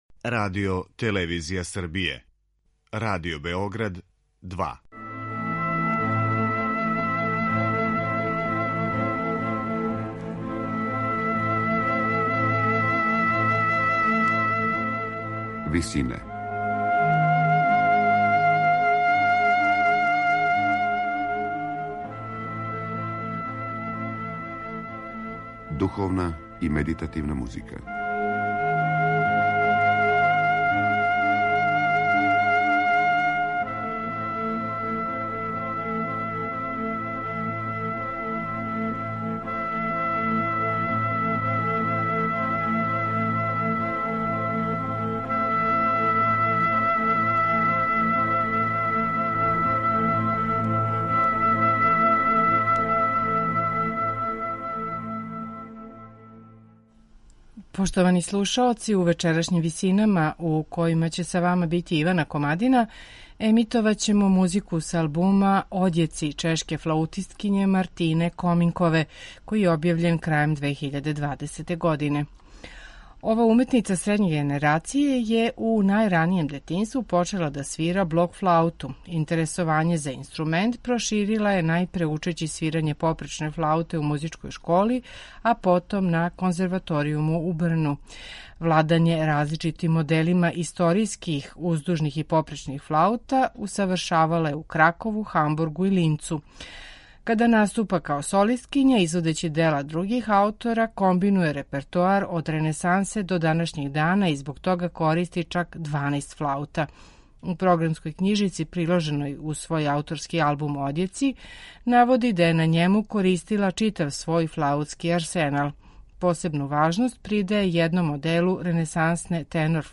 медитативне и духовне композиције
која изводи све деонице флауте и пева
оргуљаш